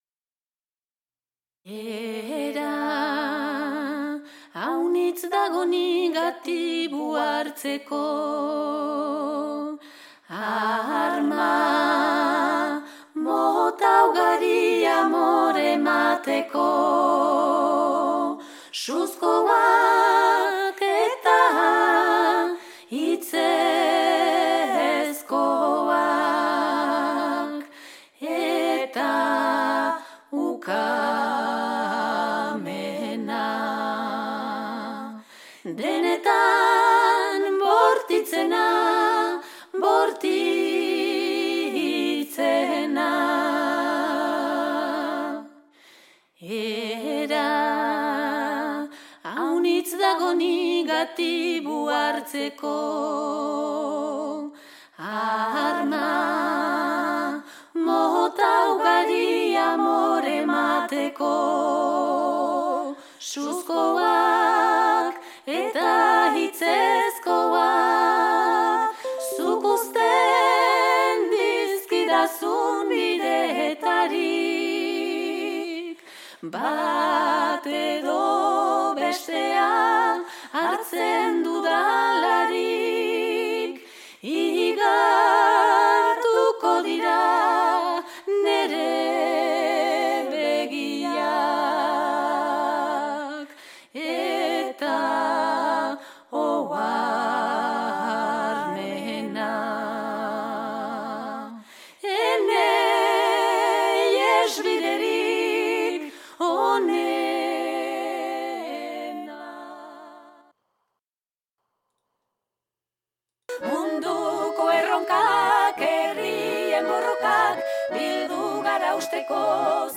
abesti tradizionalak, abeslaritza, a capela, herri abestiak